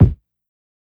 Kicks
KICK_YUCK.wav